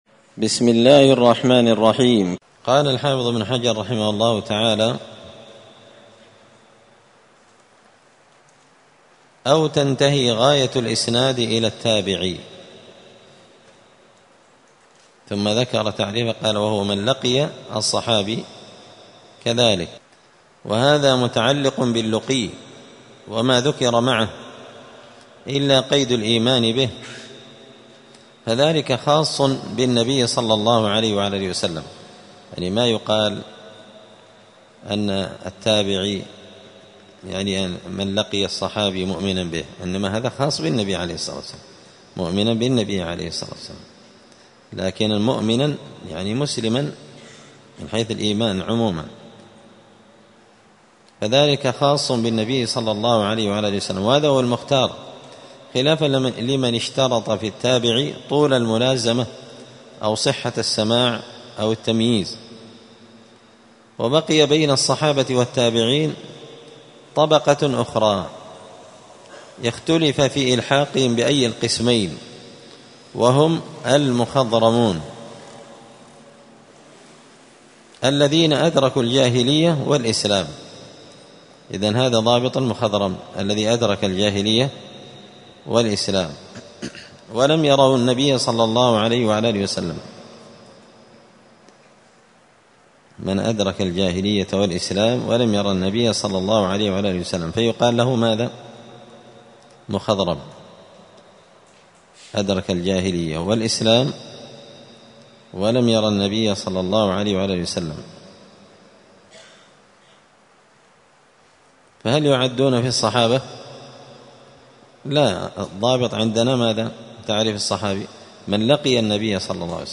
تعليق وتدريس الشيخ الفاضل:
السبت 15 ربيع الأول 1445 هــــ | الدروس، دروس الحديث وعلومه، نزهة النظر بشرح نخبة الفكر للحافظ ابن حجر | شارك بتعليقك | 81 المشاهدات